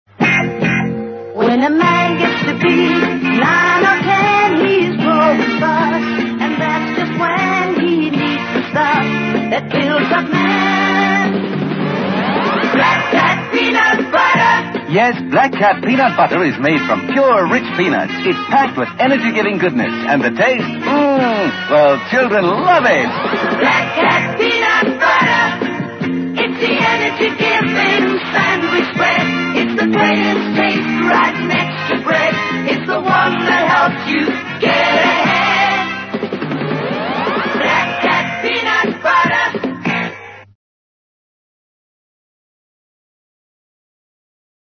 Black Cat Peanut Butter Ad [Audio 🎵]